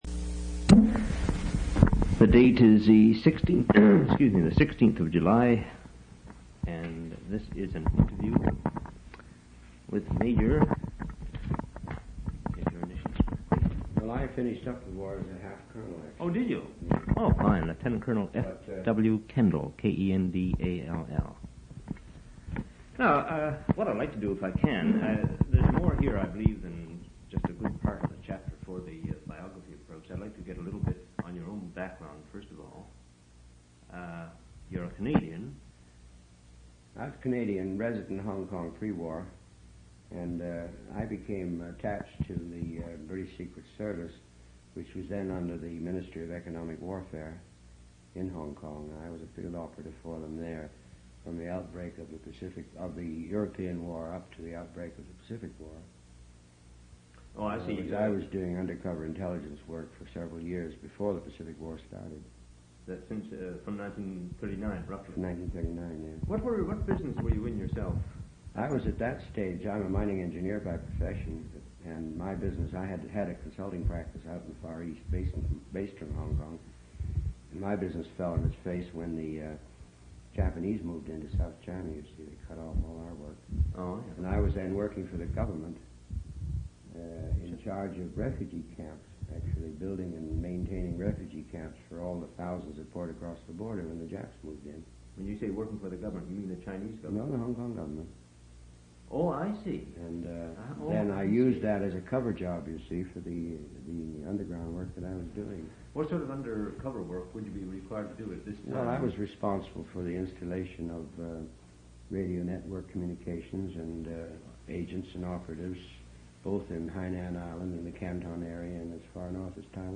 Personal narratives--Canadian Military history